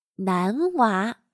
男娃/Nánwá/[En el habla coloquial] chico.